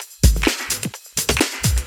Index of /VEE/VEE Electro Loops 128 BPM
VEE Electro Loop 154.wav